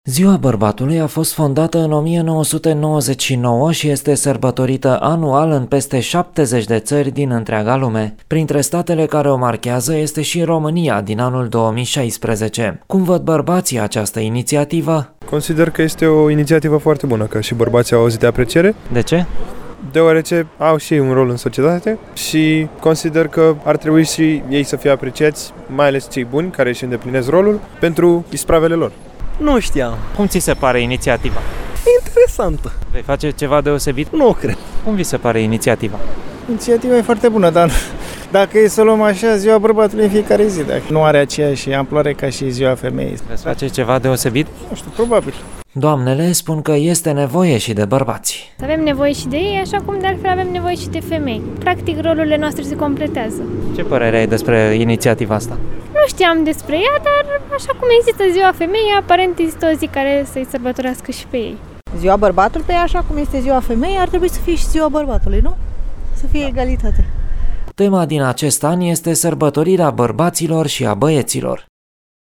a aflat care este părerea constănțenilor despre această inițiativă.